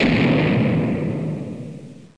3. 시간이 지나면 폭탄이 터지는 소리가 난다.
16. 폭탄 터지는 소리를 만들기 위해 [소리] 컴포넌트를 가져온다.